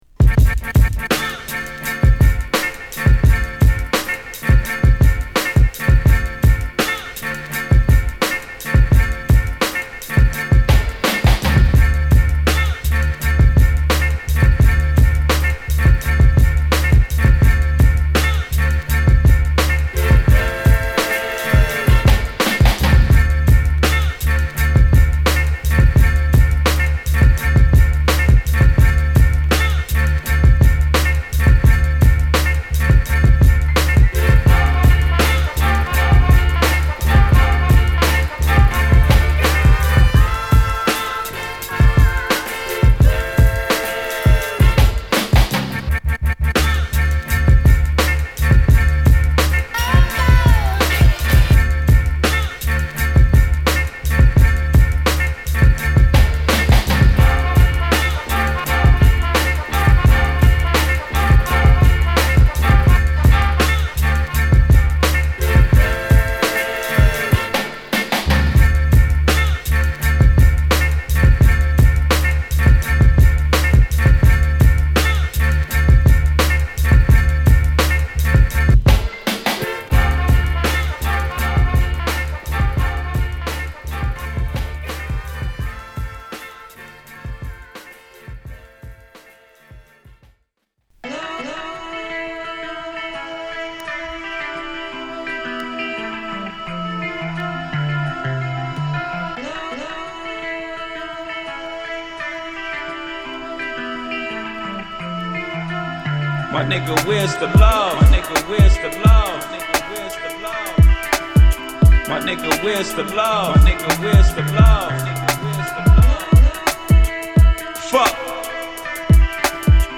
ビートメイカーとしてのプライドを感じる『インスト』アルバムが待望のリリース！